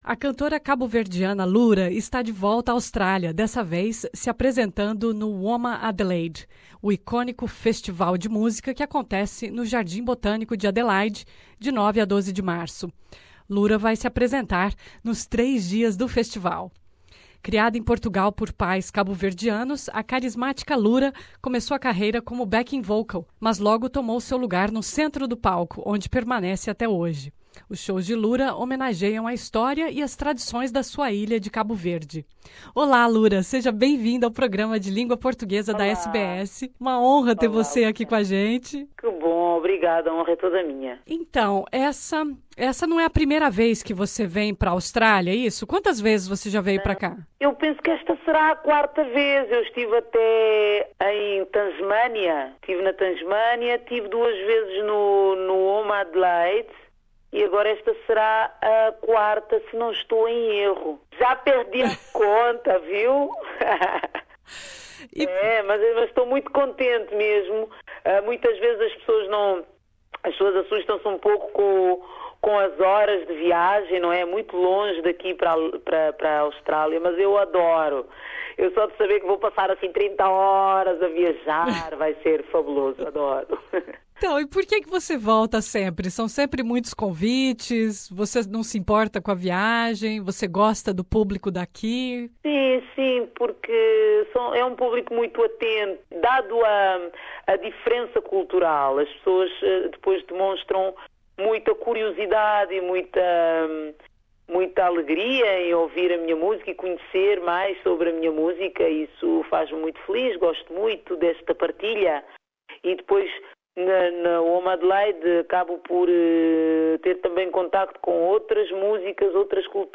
A cantora caboverdiana estará no festival WOMADelaide 2018. Nessa entrevista Lura nos fala da paixão que tem pelo Brasil, a parceria com Naná Vasconcelos, as semelhanças entre o funaná e o maracatu e descreve a experiência inesquecível que foi animar o carnaval de Recife, acompanhada por 600 bateiristas.